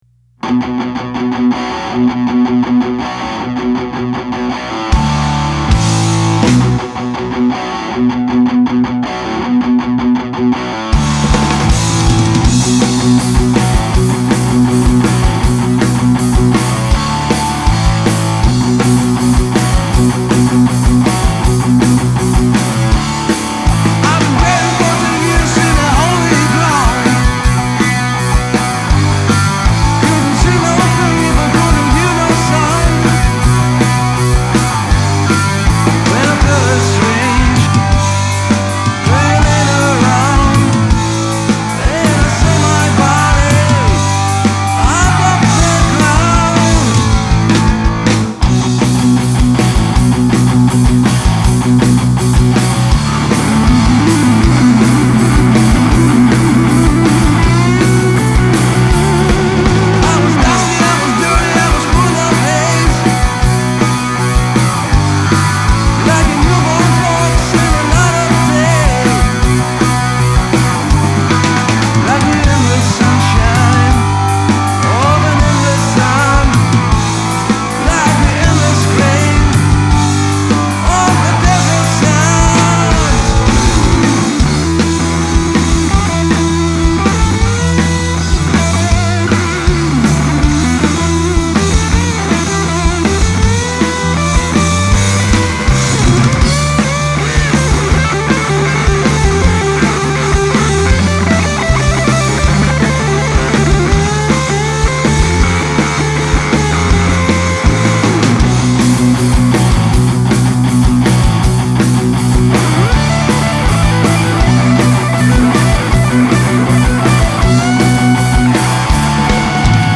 rock n roll
τύμπανα
κιθάρα, φυσαρμόνικα
μπάσο, φωνή